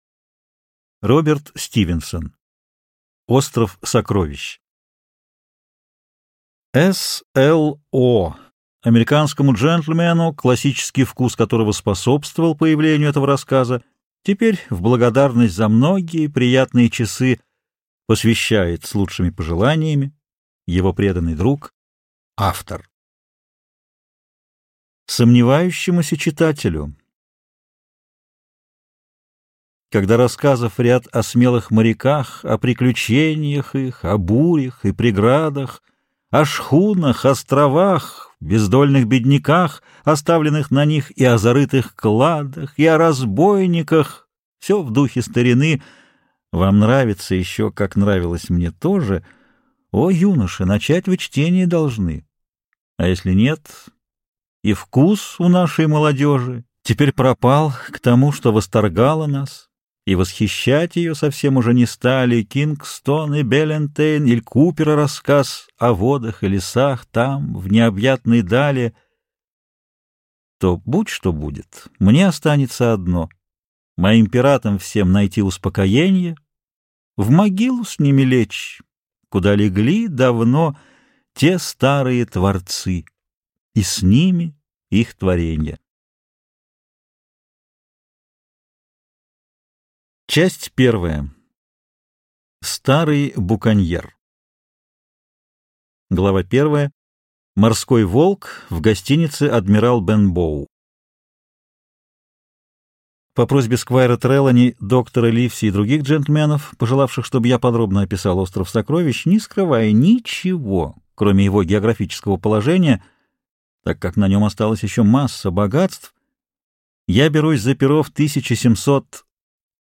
Аудиокнига Остров сокровищ | Библиотека аудиокниг